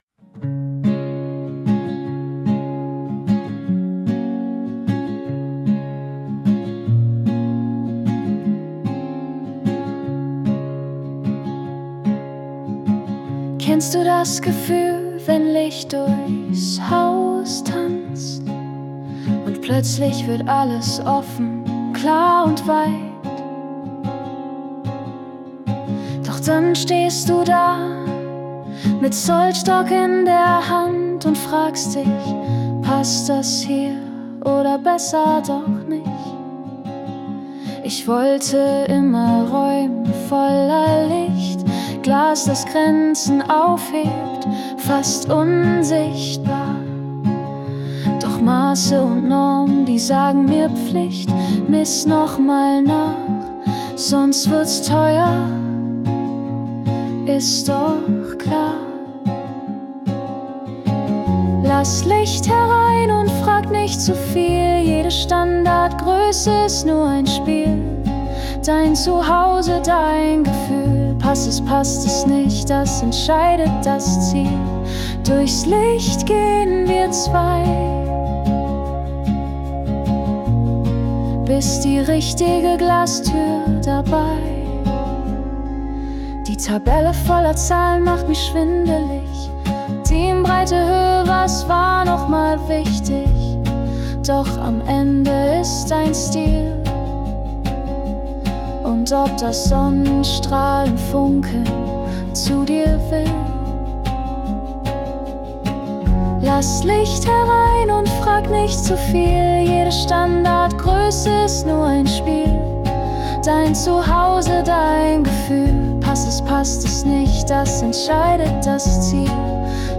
einen kleinen passenden Song zum Thema aufgenommen